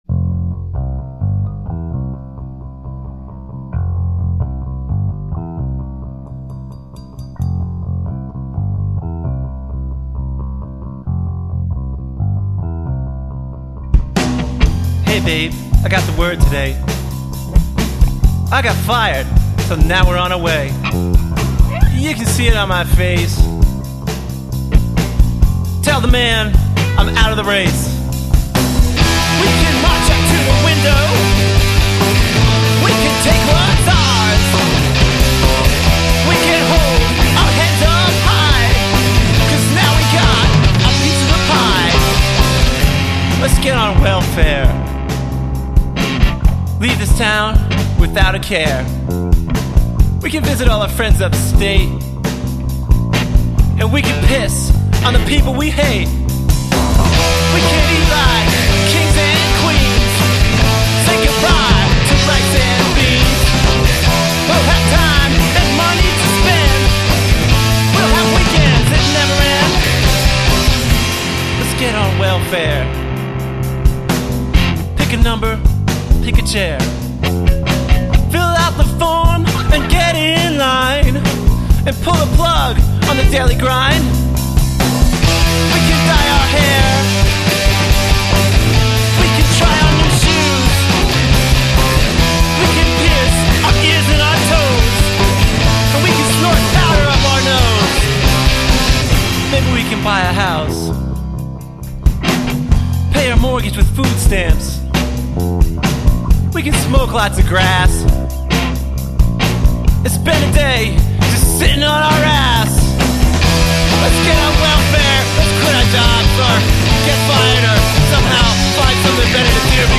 Vocals, Tin Whistle
Guitar, Vocals
Bass
Drums